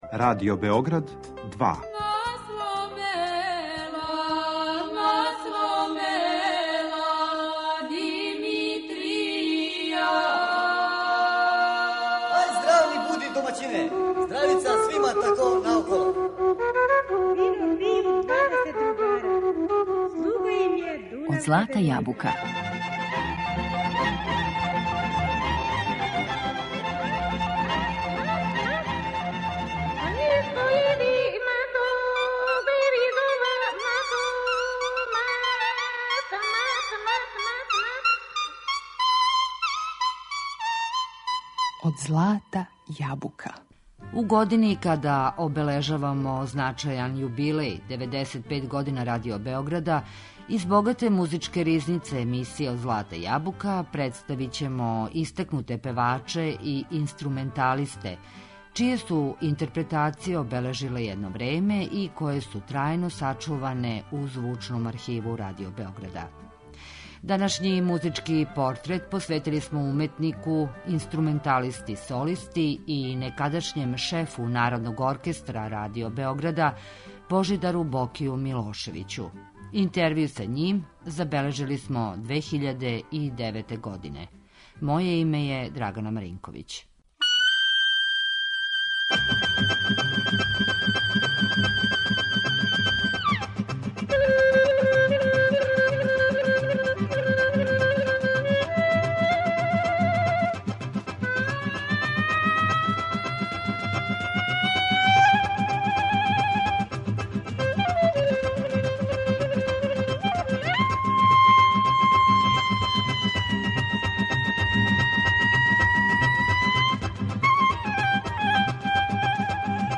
Интервју са уметником забележили смо 2009. године.